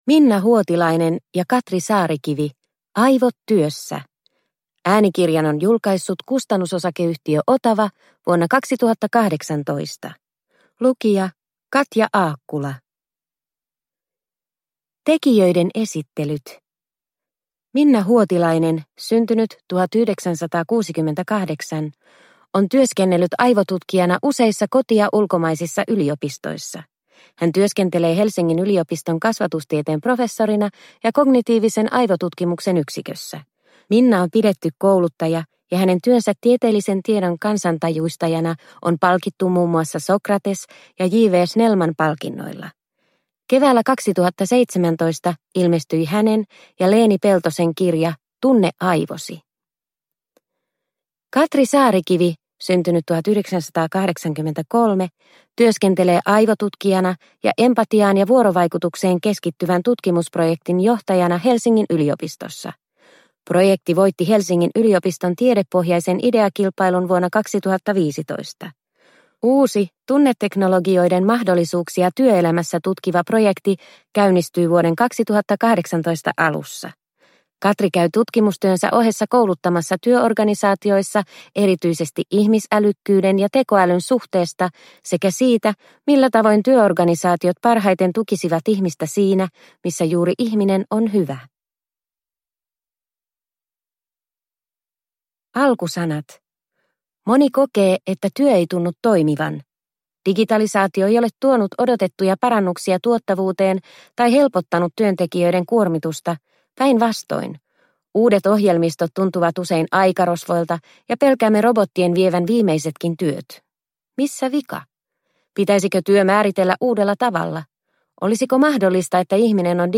Aivot työssä – Ljudbok – Laddas ner